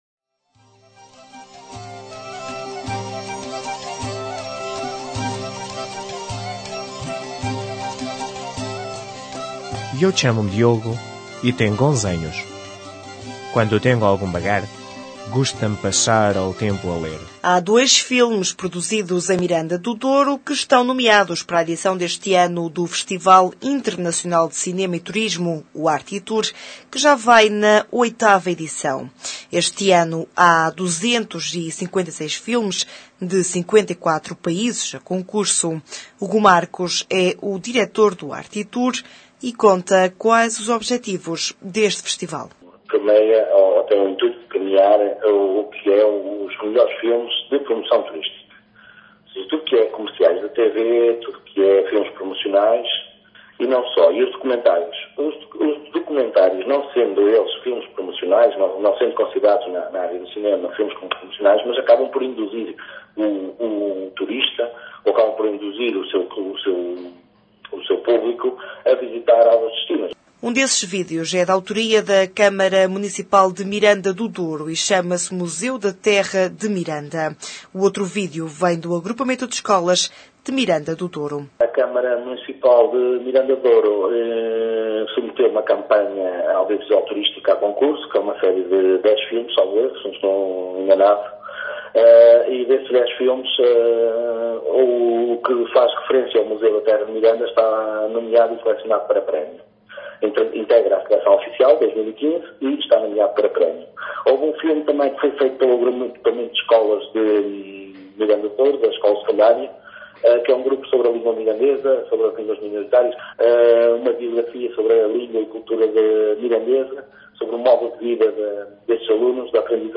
Informação Regional